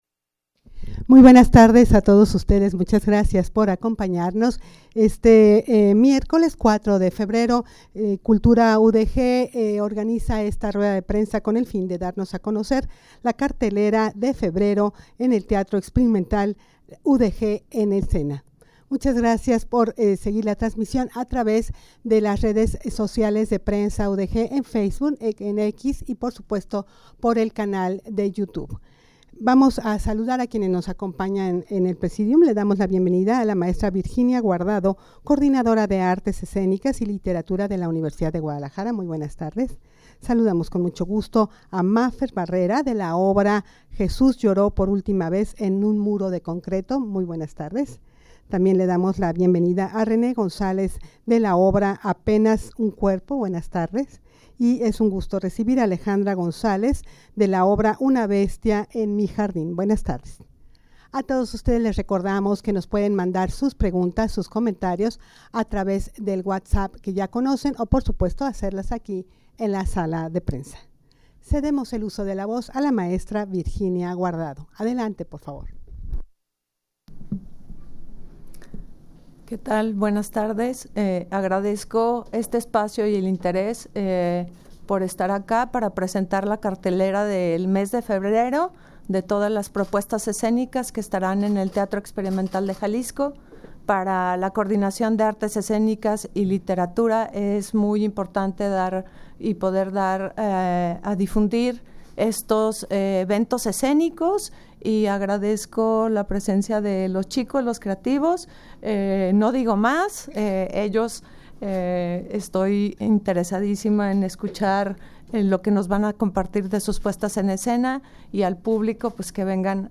rueda-de-prensa-presentacion-de-obras-de-teatro-febrero-udeg-en-escena.mp3